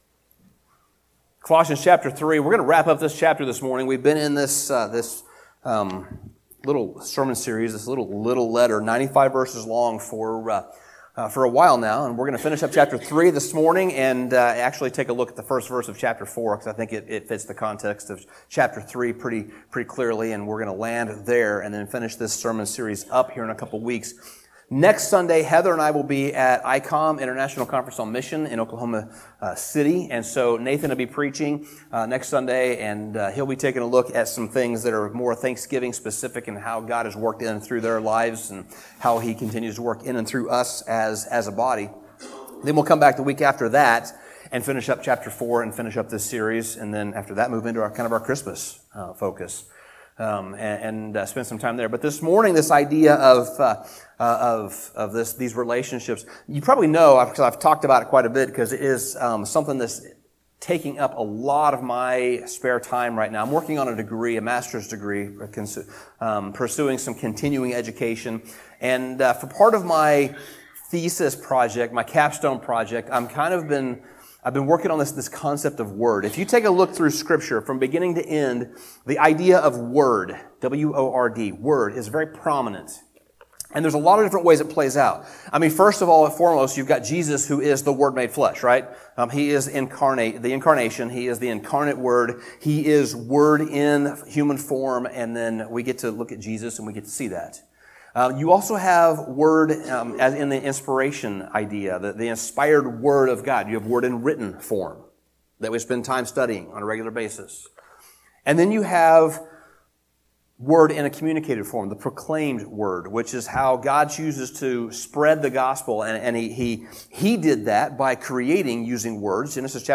Sermon Summary Chapter 3 begins the application part of Paul's letter to the Colossian church, and his application is practical for every aspect of everyday life, and for every relationship we have, including our marriages, our parenting, and our careers.